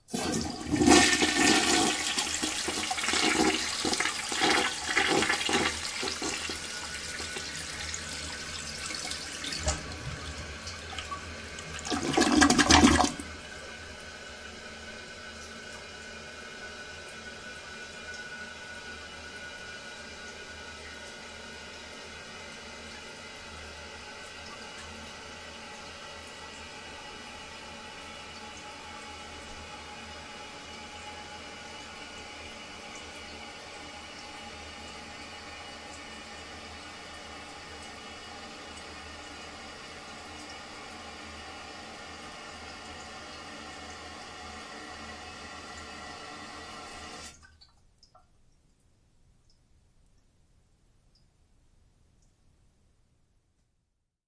小便池冲水
描述：小便池冲洗在宽敞的浴室里的水冲洗和水的涓涓细流。记录在Zoom H1上
Tag: 现场录音 冲洗 水运行 浴室